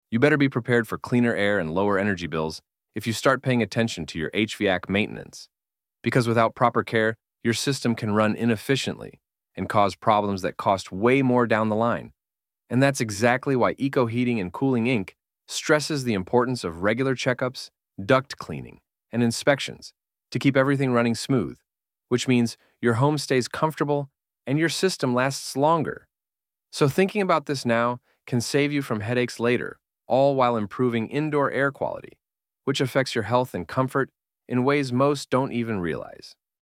81041-voiceover.mp3